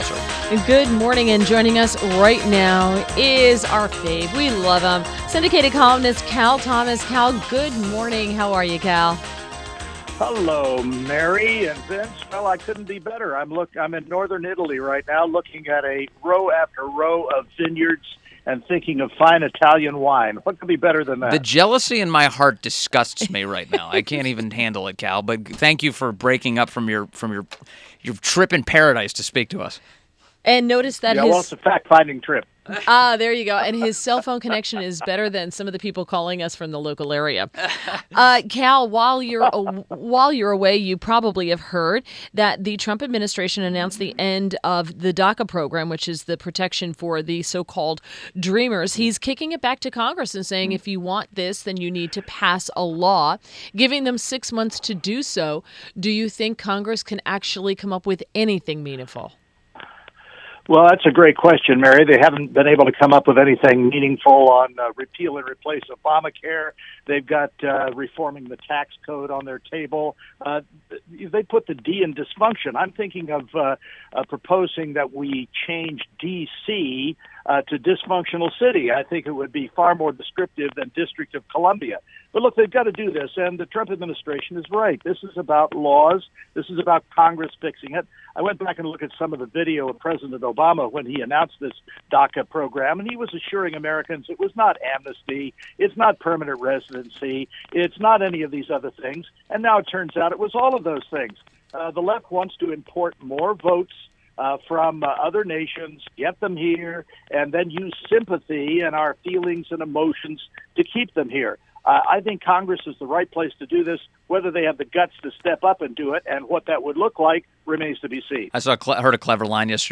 6:35 - INTERVIEW -- CAL THOMAS - syndicated columnist